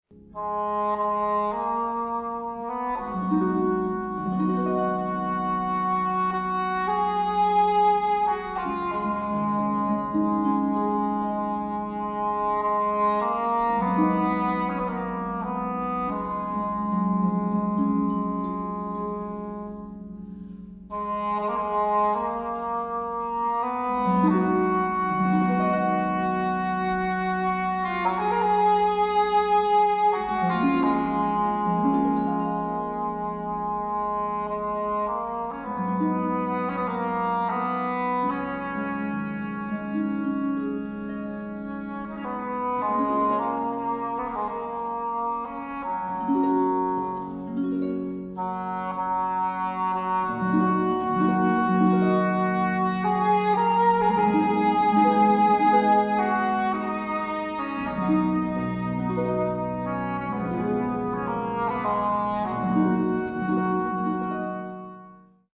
16 folk melodies, including:
over a simple string line, the drone and ornamentation giving a celtic tinge to the english melancholy.